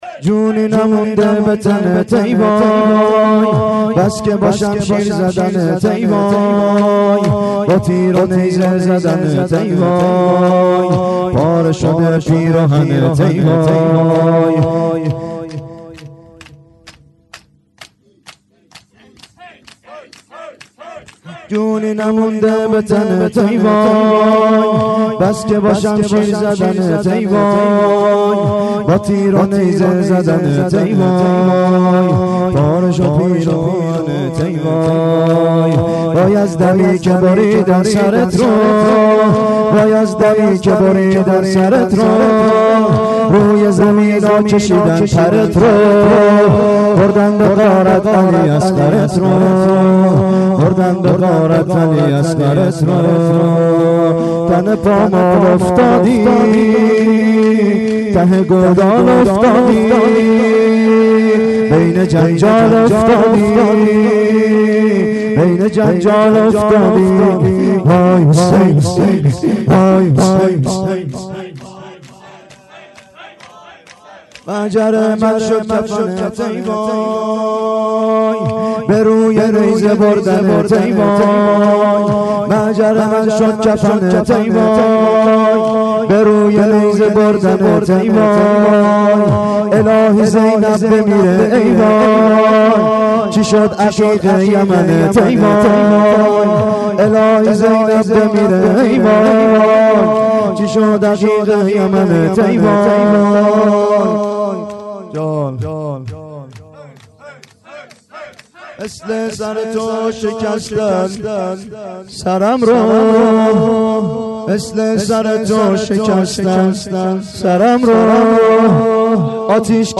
گلچین شورهای محرم 93
شور شب پنجم : جونی نمونده به تنت ای وای بس که با شمشیر زدنت ای وای